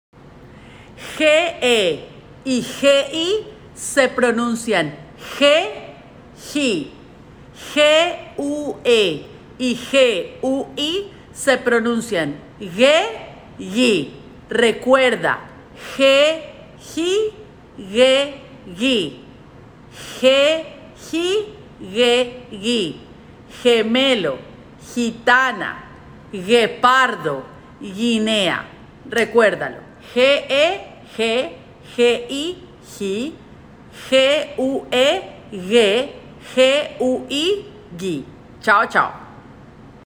Letter G pronunciation in Spanish